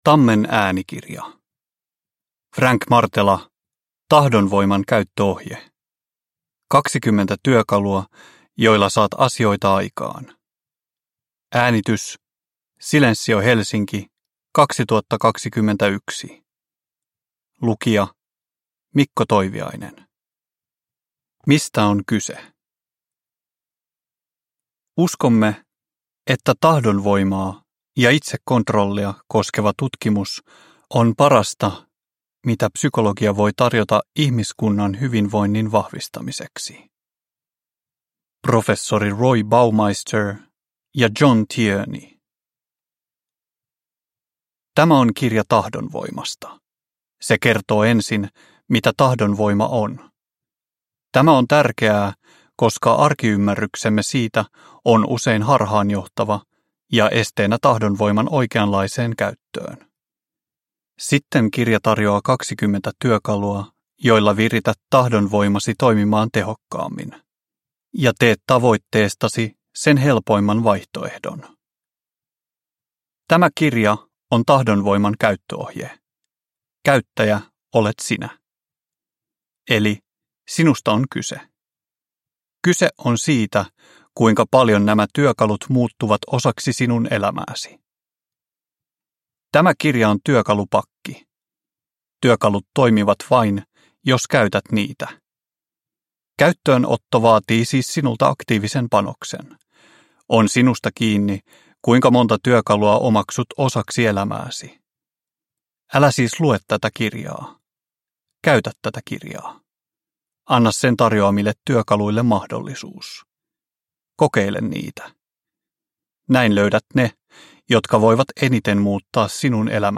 Tahdonvoiman käyttöohje – Ljudbok – Laddas ner